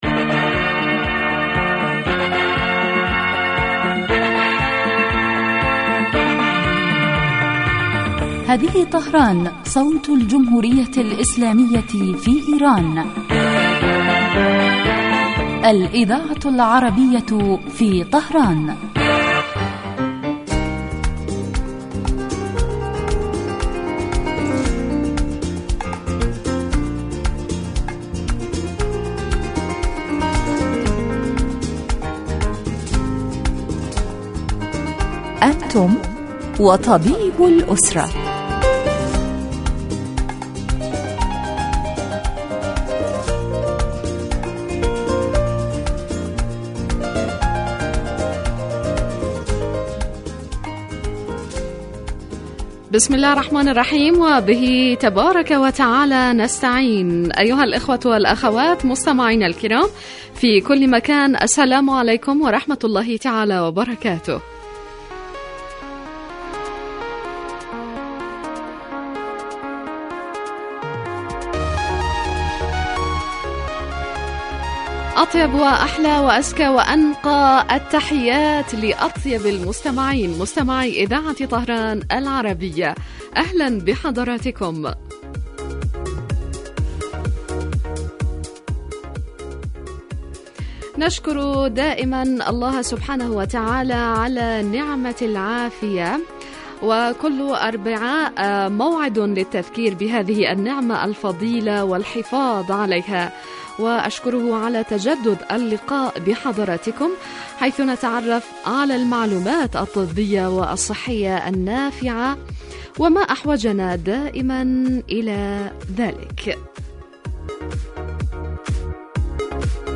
يتناول البرنامج بالدراسة و التحليل ما يتعلق بالأمراض و هو خاص بالأسرة و يقدم مباشرة من قبل الطبيب المختص الذي يرد كذلك علي أسئلة المستمعين و استفساراتهم الطبية